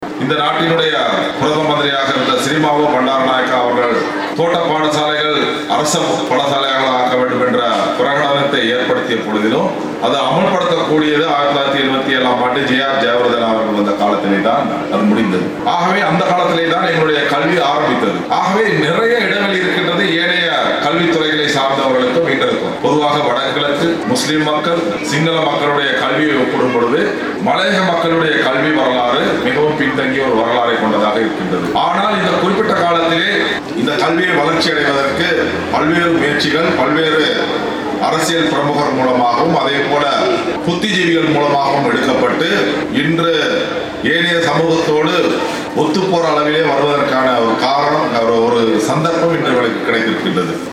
ஹட்டன் பிரதேசத்தில் இடம்பெற்ற நிகழ்வில் கலந்து கொண்டு உரையாற்றிய போதே அவர் இதனை தெரிவித்தார்.